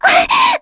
Mediana-Damage_02.wav